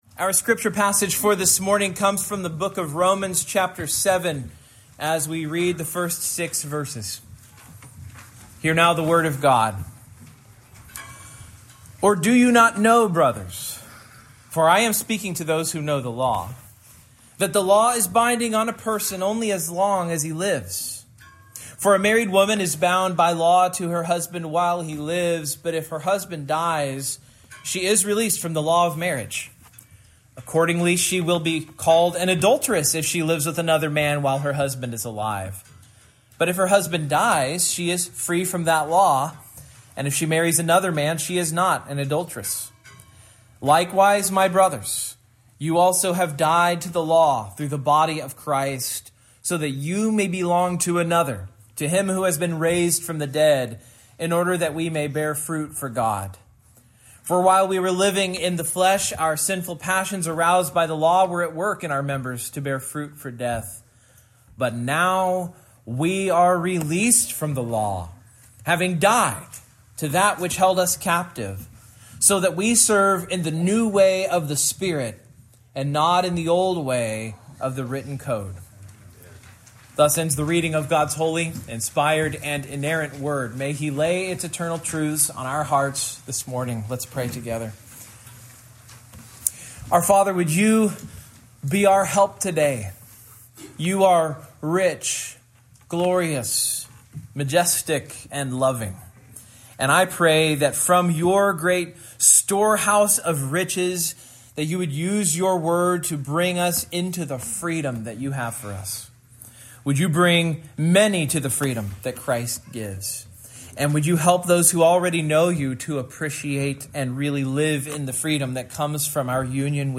Romans 7:1-6 Service Type: Morning Main Point